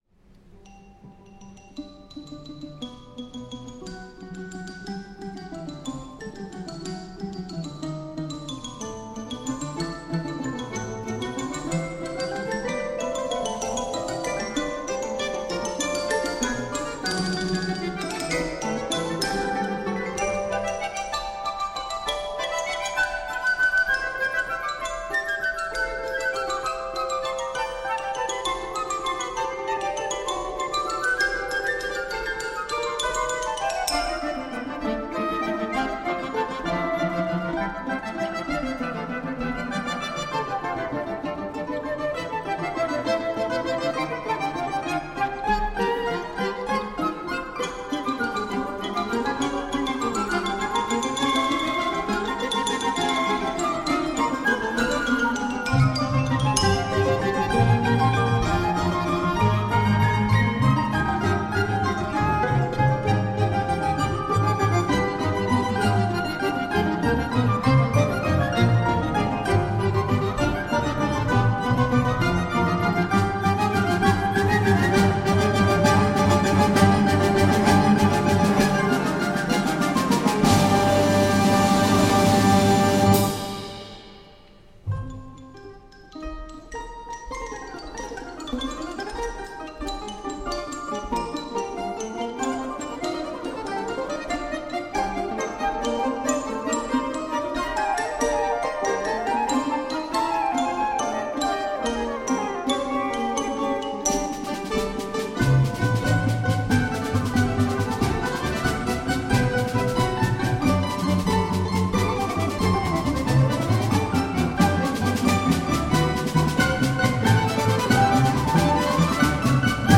каждая мелодическая линия - по сути танцевальная